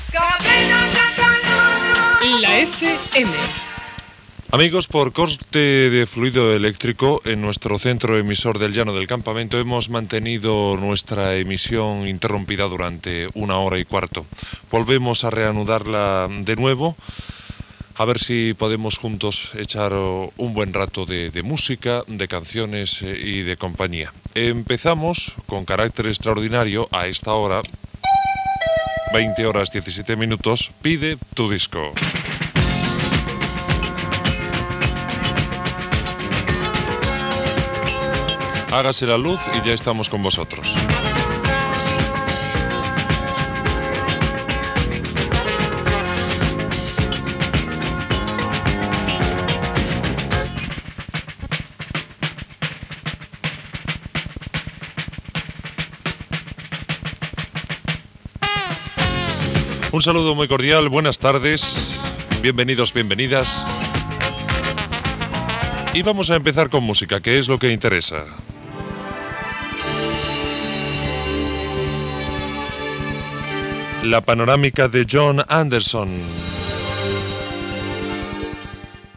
Indicatiu i represa després d'un tall d'emissió.
Musical